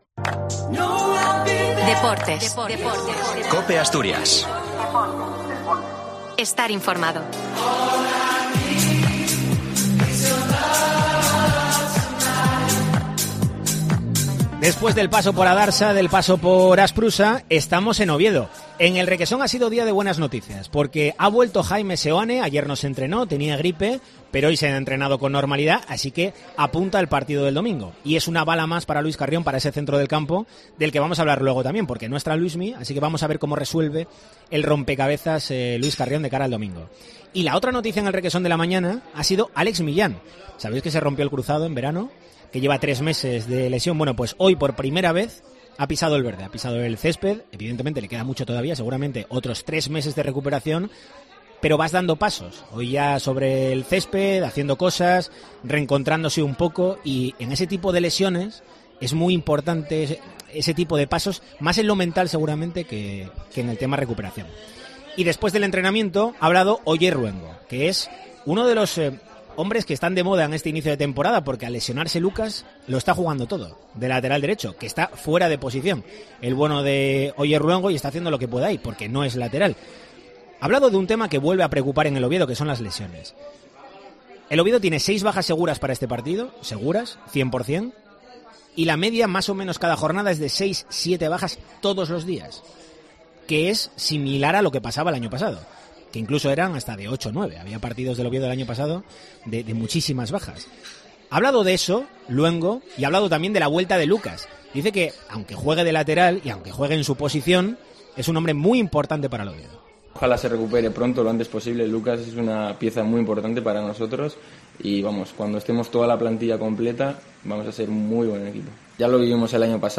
¿Encaja mejor el estilo de Carrión con la idiosincrasia del club o es más acorde a la historia lo que proponían Ziganda y Cervera? No te pierdas un nuevo capítulo de ' La Tertulia del Oviedo ' en Deportes COPE Asturias desde la sidrería La Espita , en Colloto.